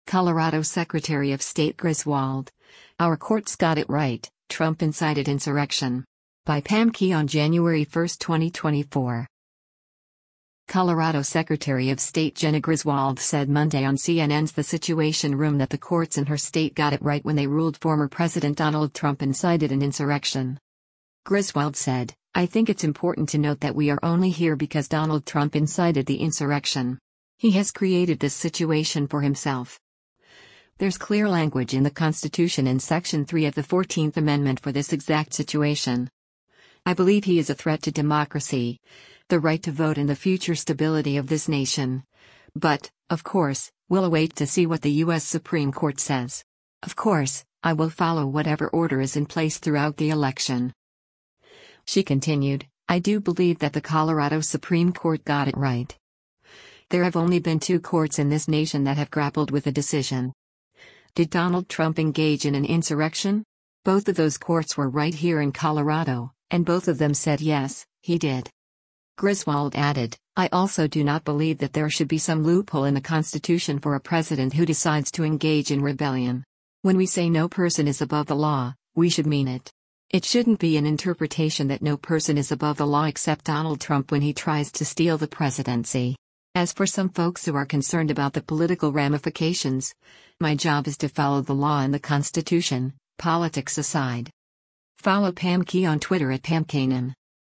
Colorado Secretary of State Jena Griswold said Monday on CNN’s “The Situation Room” that the courts in her state “got it right” when they ruled former President Donald Trump incited an insurrection.